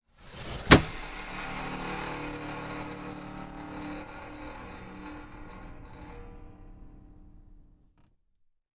抢夺餐具01
描述：从抽屉里抓起餐具。使用Sennheiser MKH416和Sound Devices 552录制。
标签： 弹性 boingy 金属 博英 弹簧 鼻音 电线 玩具
声道立体声